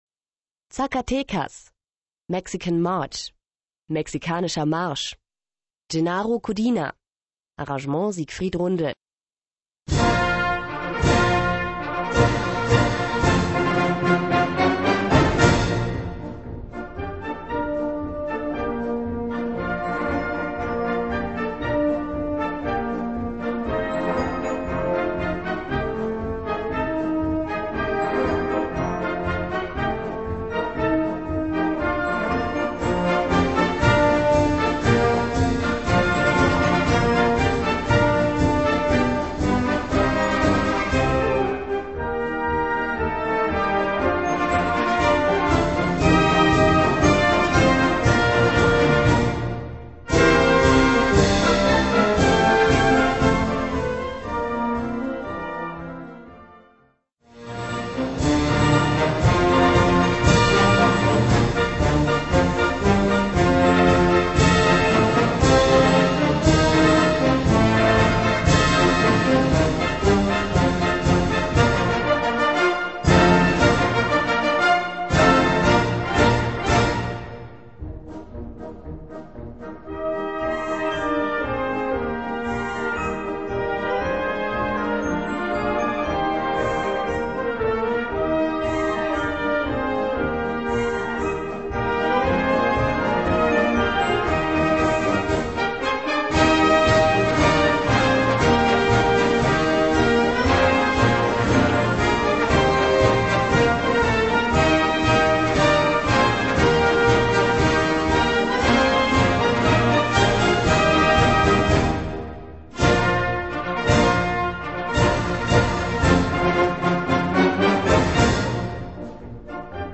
Gattung: Mexicanischer Marsch
Besetzung: Blasorchester